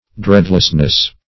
Dreadlessness \Dread"less*ness\, n.